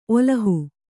♪ olahu